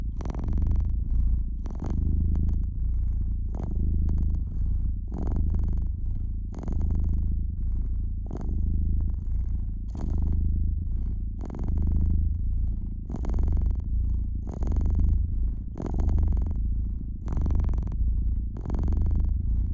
purr.mp3